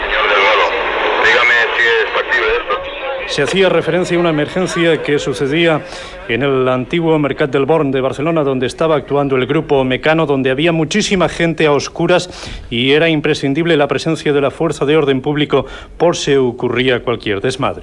Indicatiu del programa, informació d'una apagada elèctrica general a la ciutat de Barcelona.
Informació des de la central de la Guàrdia Urbana de Barcelona.
Informatiu